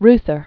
(rthər), Walter Philip 1907-1970.